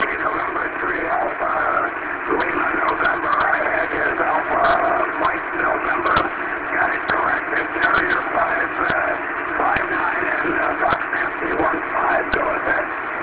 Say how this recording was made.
The following table has some recordings I made from the event.